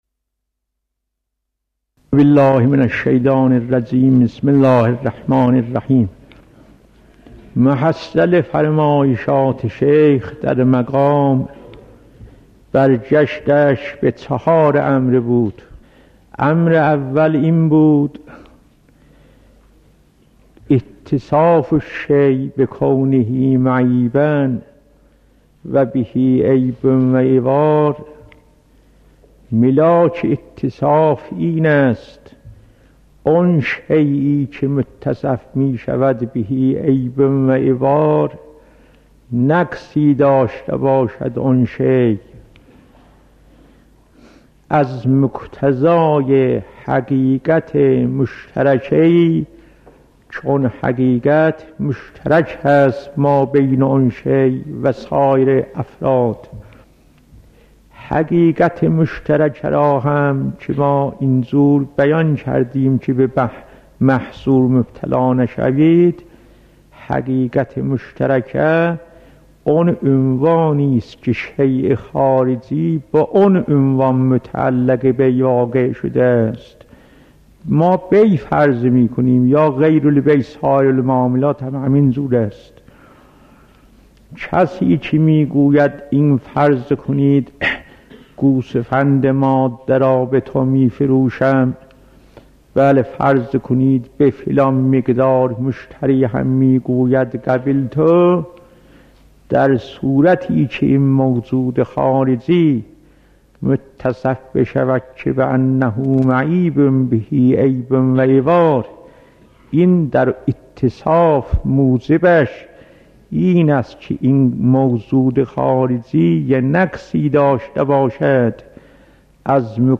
آیت الله جواد تبريزي - خيارات | مرجع دانلود دروس صوتی حوزه علمیه دفتر تبلیغات اسلامی قم- بیان